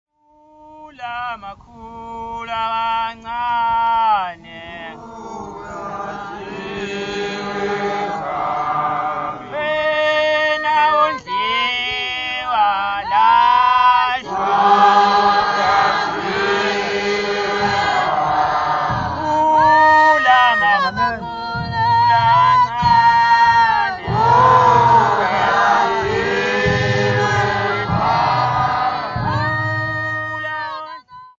Members of the Church of Nazareth (Performers)
Hymns, Zulu
Drums
Horn (Musical instrument)
Christian dance
field recordings
Three Zulu hymns for christian dancing with Izigubu drums and Umbungu horns